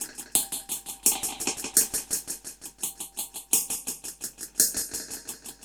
Index of /musicradar/dub-drums-samples/85bpm
Db_DrumsA_HatsEcho_85_03.wav